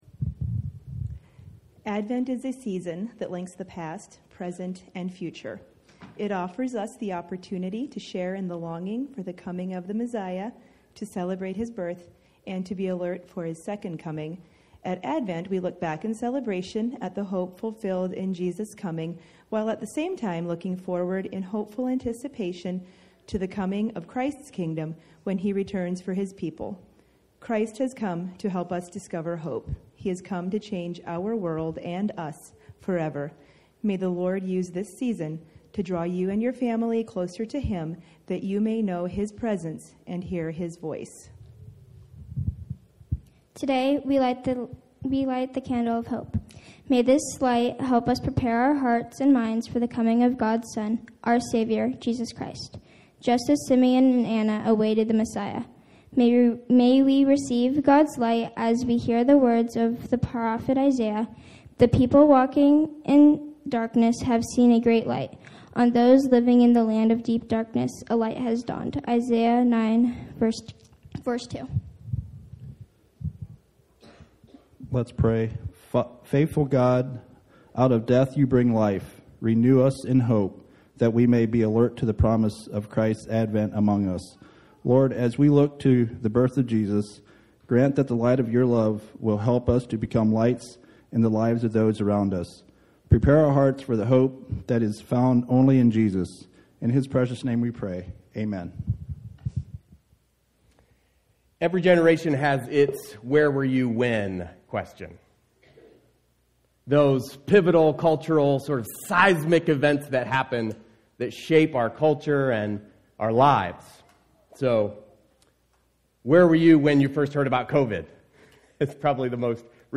The first of four advent sermons.